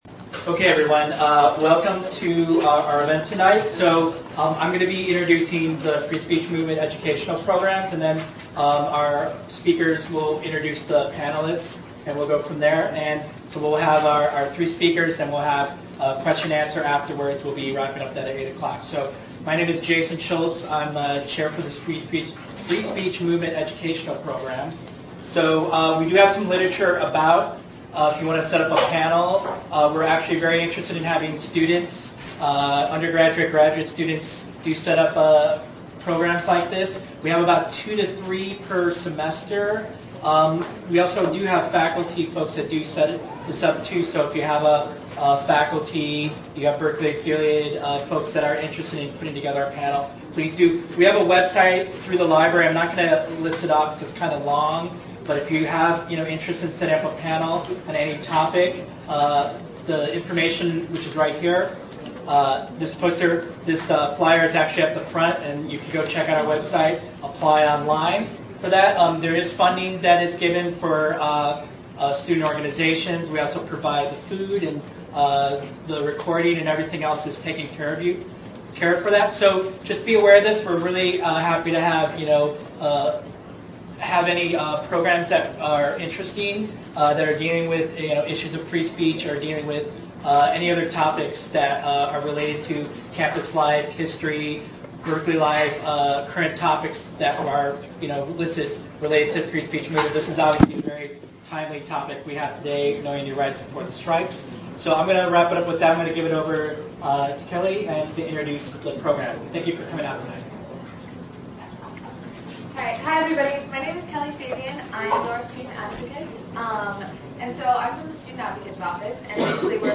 On Tuesday, October 5th, at the Free Speech Movement Cafe in UC Berkeley's Moffitt library, the Berkeley ACLU and the ASUC Student Advocate's Office presented a "Know Your Rights" panel in advance of the October 7th National Mobilization for Education.